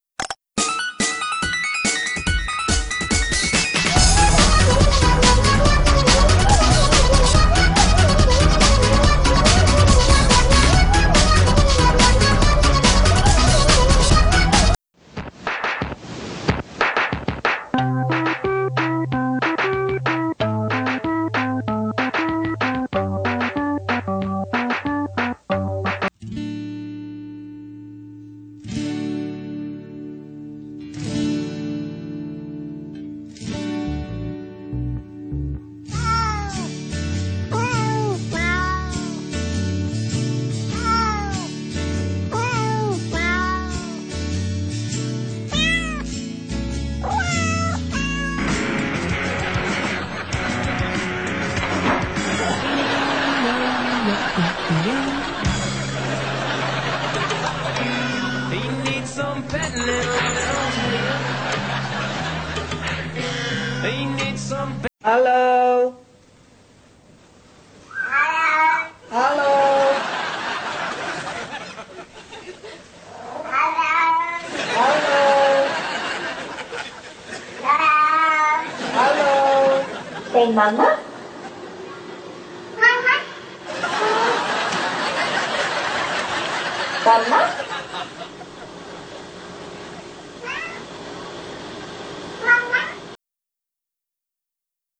For some reason when the videos load it plays a few seconds of audio from all the videos together.